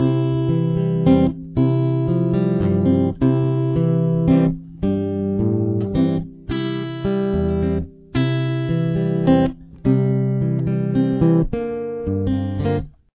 classic country song